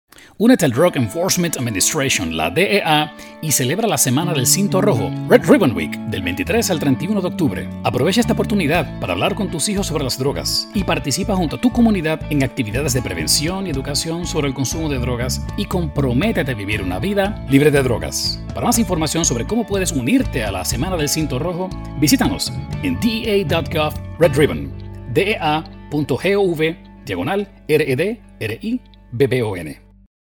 Red-Ribbon-Week-Radio-PSA-Spanish.mp3